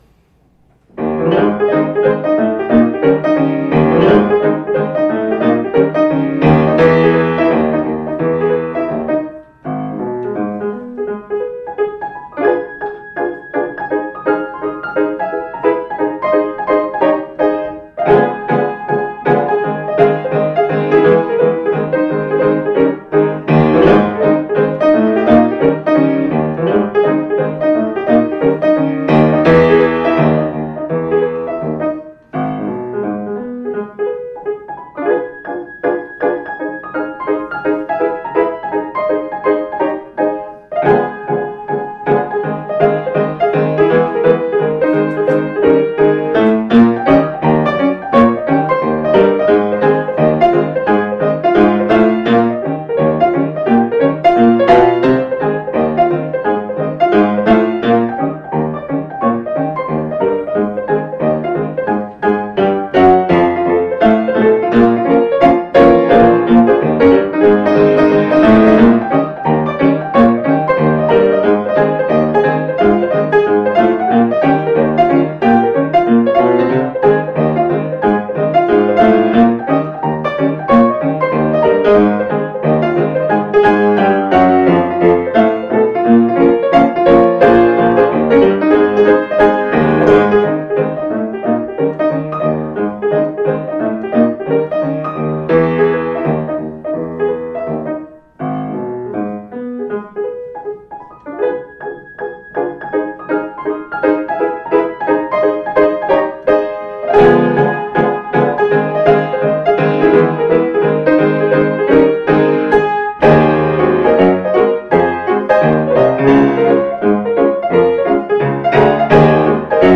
OFFERTORY -  Maple Leaf Rag  - Scott Joplin
and probably represents the quintessential example of ragtime.